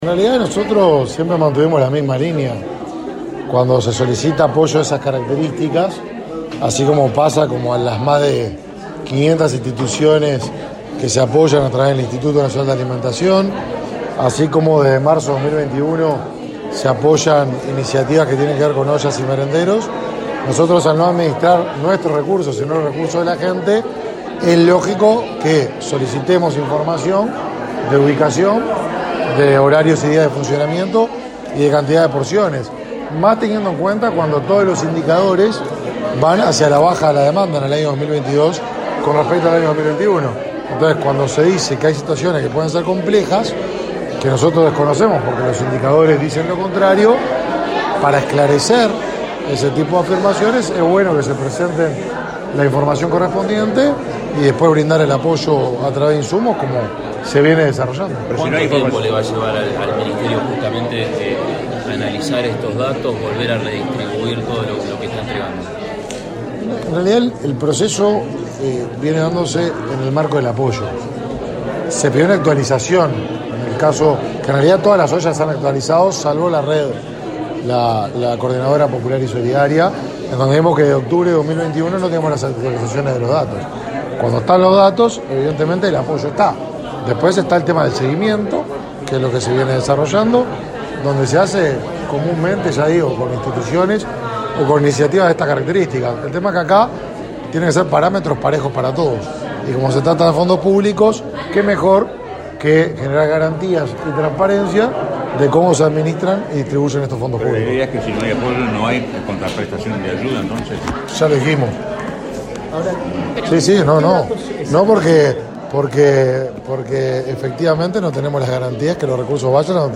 Declaraciones del ministro de Desarrollo Social, Martín Lema
Declaraciones del ministro de Desarrollo Social, Martín Lema 29/09/2022 Compartir Facebook X Copiar enlace WhatsApp LinkedIn La director nacional de Desarrollo Social, Cecilia Sena, y el ministro Martín Lema presentaron este jueves 29 la rendición de cuentas respecto al monotributo social. Luego, Lema dialogó con la prensa.